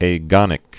(ā-gŏnĭk, ə-)